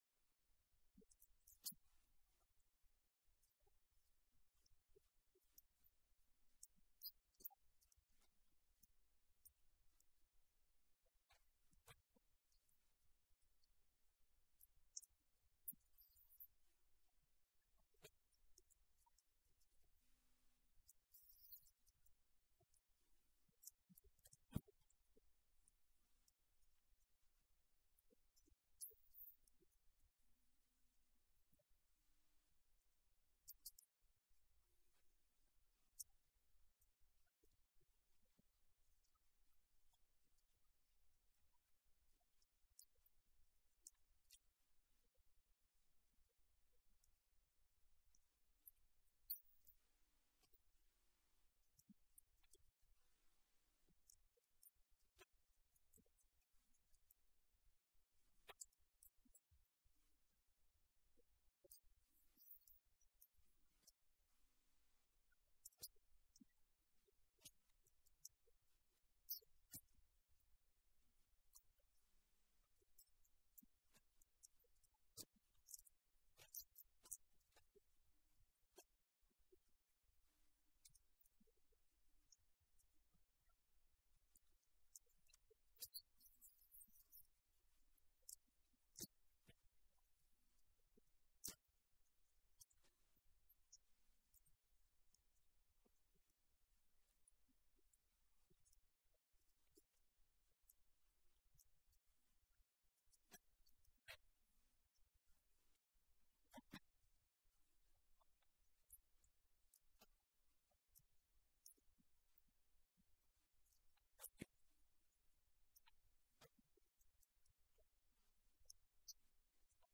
A message from the series "Reconstructing Faith." A church in action is fueled by Christians who are bought in. Looking at the Bible and the life of Jesus, He shows us that when we are bought in we will see what he sees, feel like he feels, and in response take action.